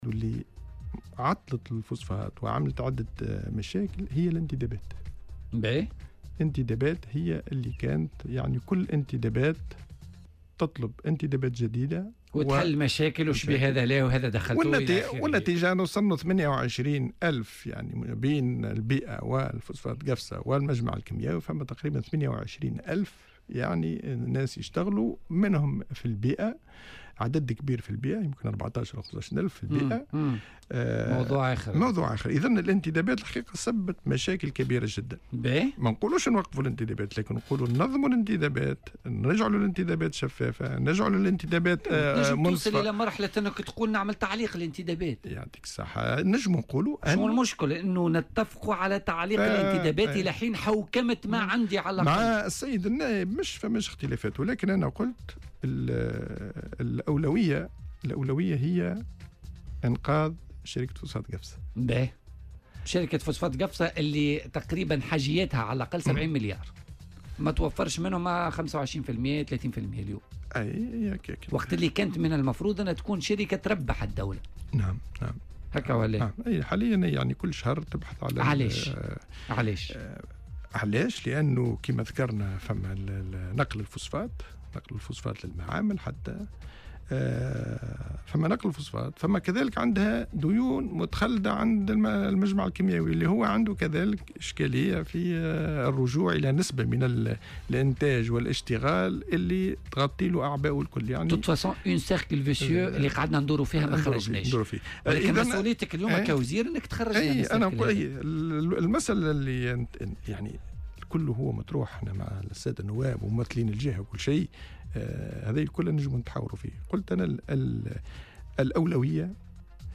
وأضاف في مداخلة له اليوم في برنامج" بوليتيكا" أن عدد المنتدبين بلغ 28 ألفا، بين شركات البيئة و فسفاط قفصة والمجمع الكيميائي.